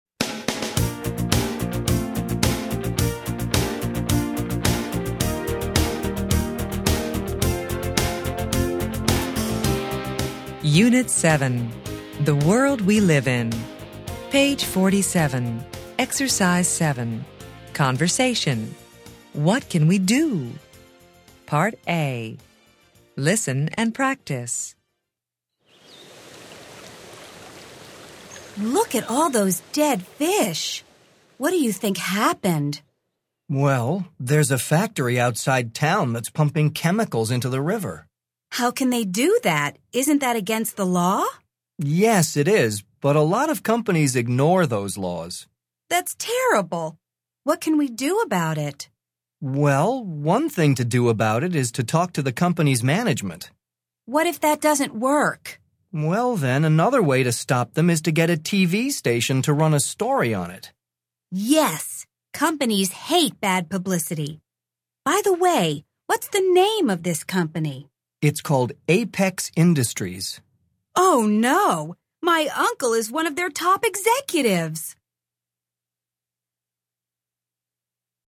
Interchange Third Edition Level 3 Unit 7 Ex 7 Conversation Track 13 Students Book Student Arcade Self Study Audio